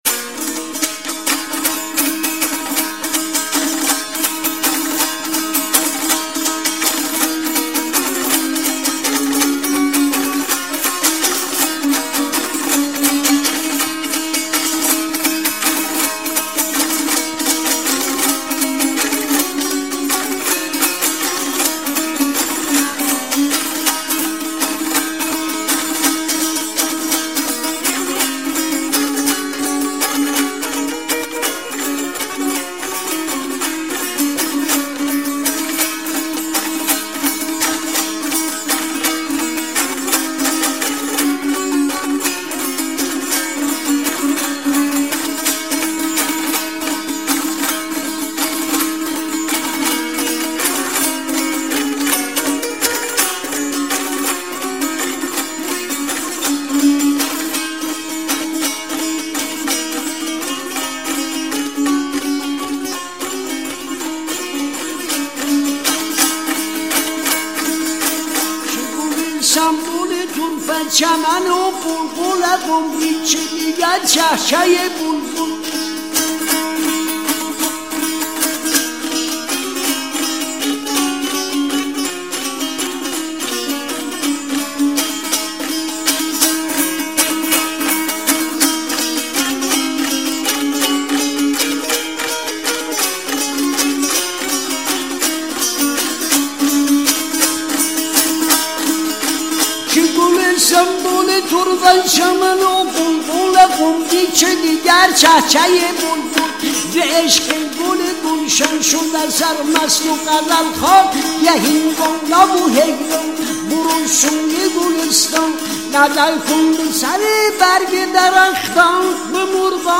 • بحر طویل